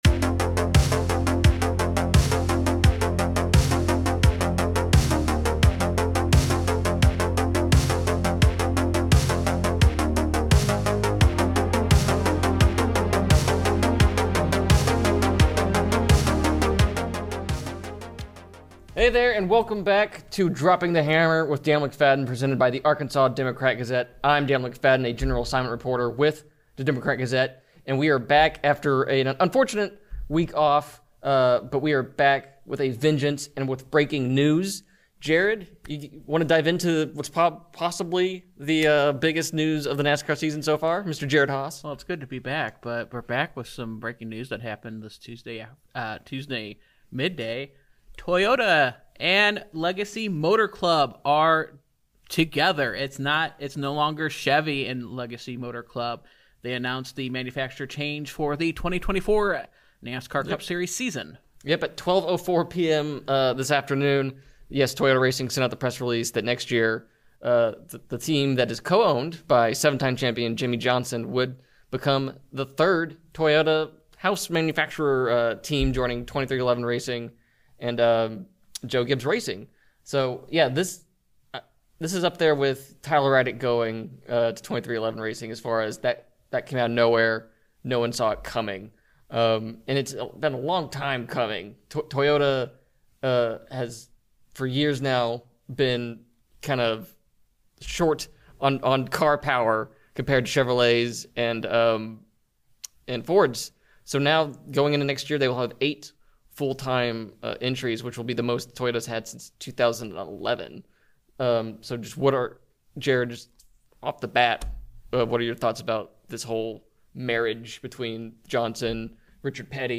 Toyota Racing Martin Truex Jr. interview audio credit: NASCAR